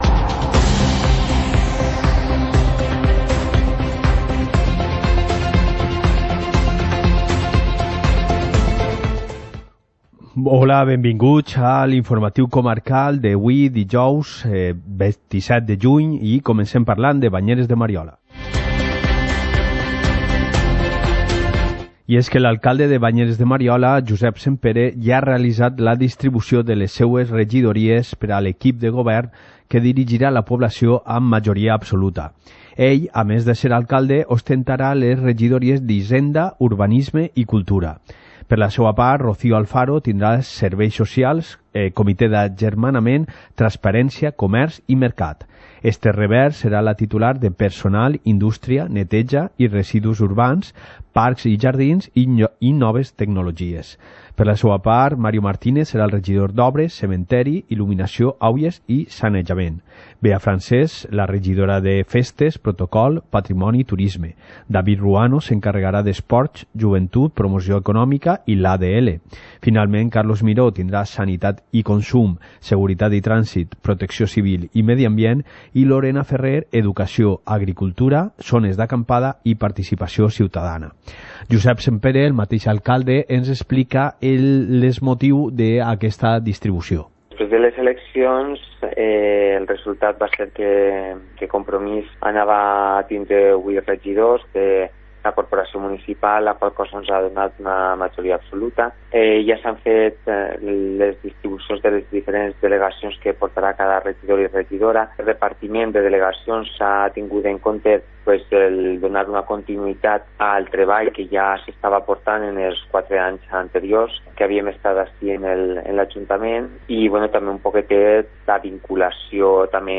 Informativo comarcal - jueves, 27 de junio de 2019